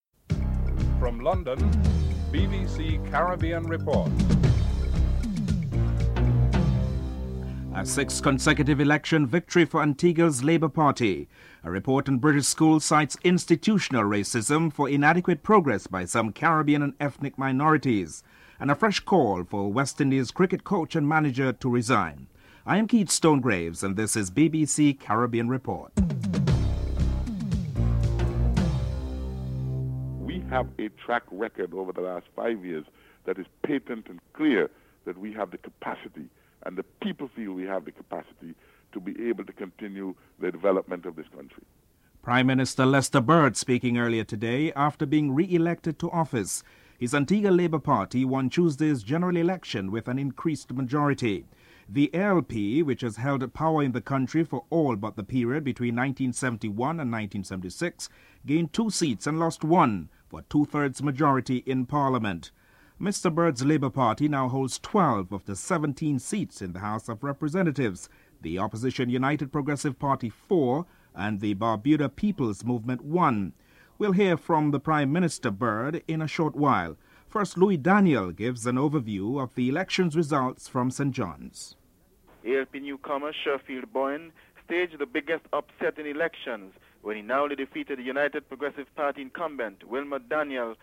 Prime Minister Lester Bird comments on his successful track record in the development of Antigua.
Former West Indies cricket captain Vivian Richards speaks